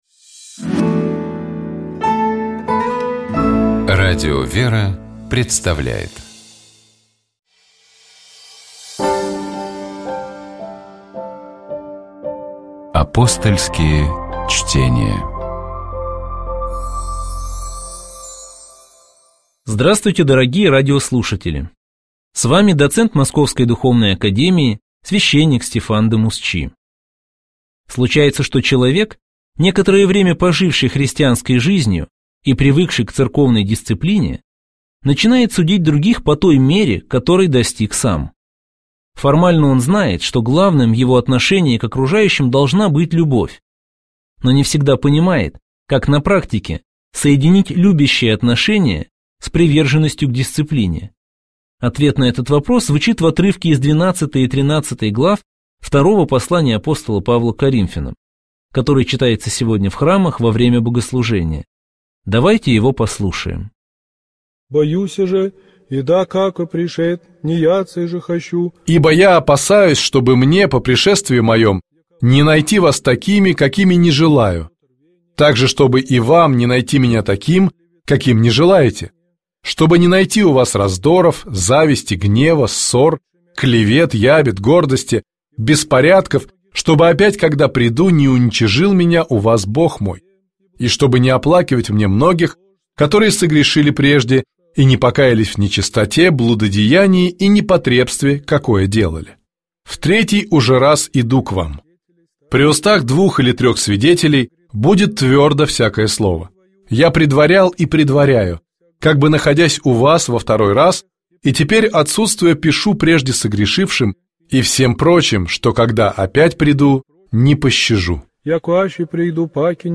Только что прозвучал фрагмент из картины, в котором Шарлотта, размышляя вслух наедине с собой, решает не задерживаться у дочери.